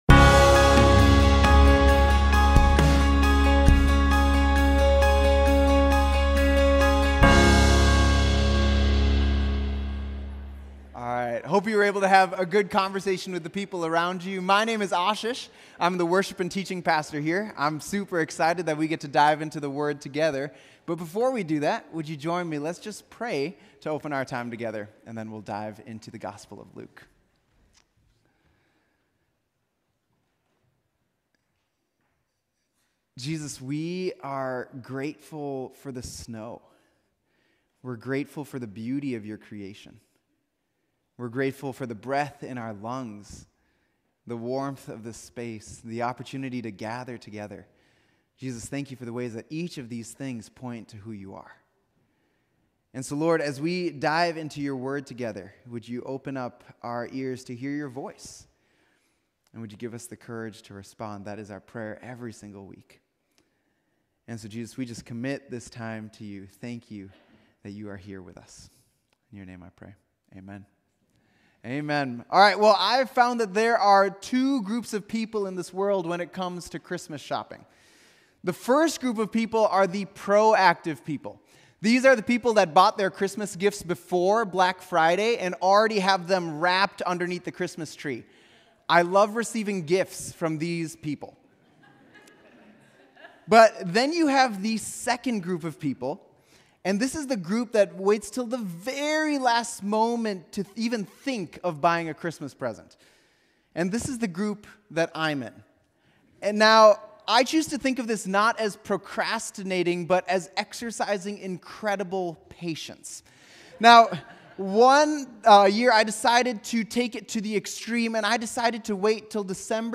Mill City Church Sermons Light in the Darkness: Peace Dec 11 2023 | 00:30:57 1x 00:00 / 00:30:57 Subscribe Share RSS Feed Share Link Embed